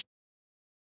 Hihat [Racks].wav